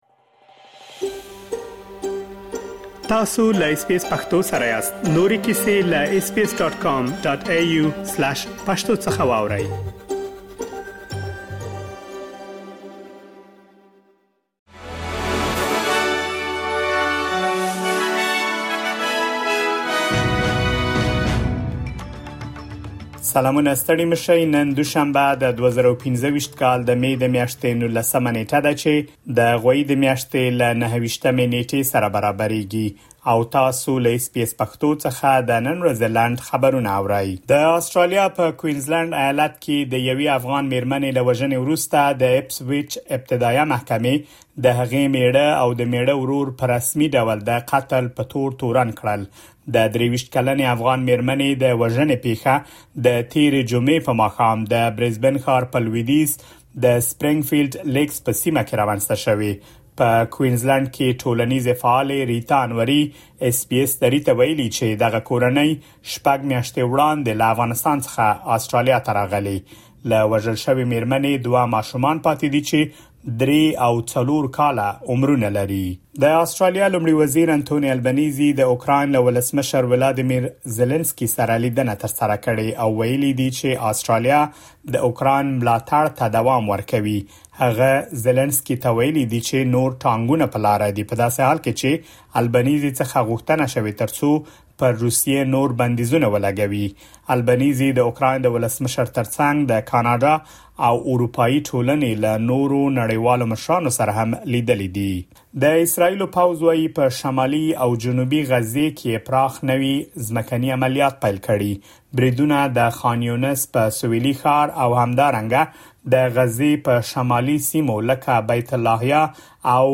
د اس بي اس پښتو د نن ورځې لنډ خبرونه | ۱۹ مې ۲۰۲۵